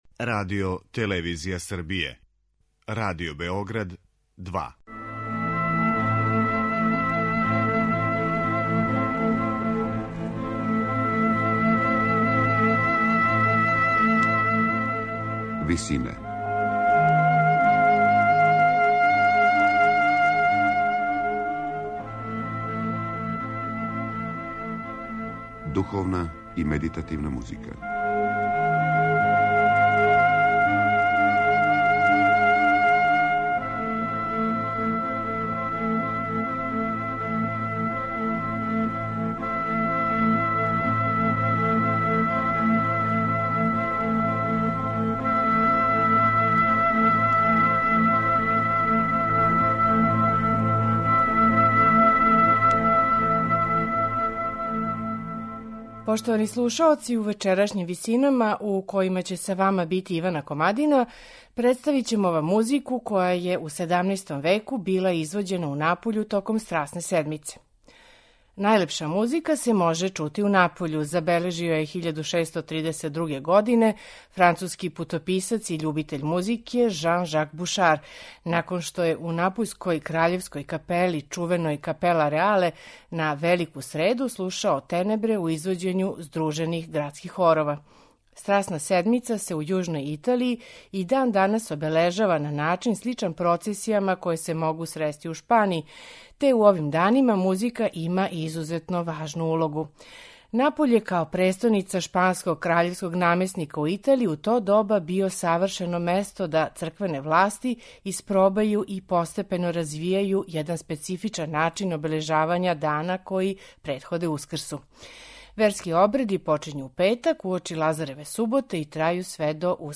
сопран и ансамбл I turchini